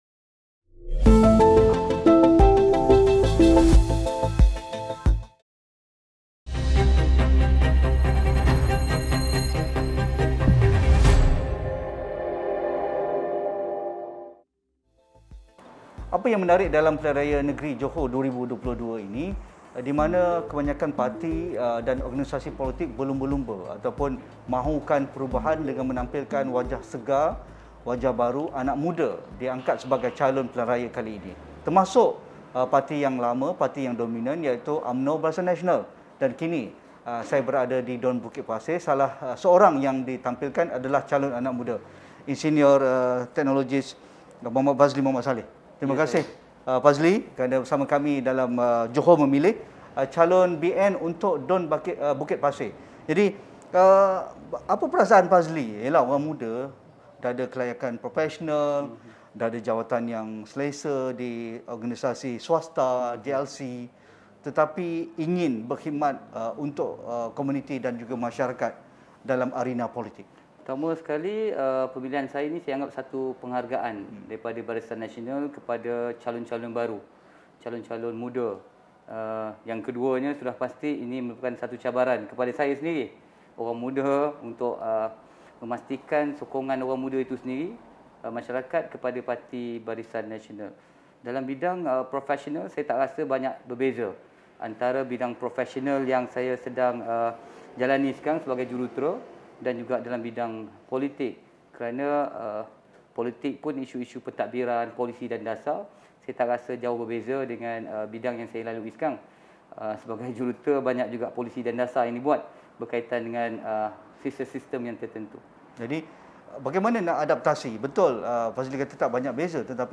Temu bual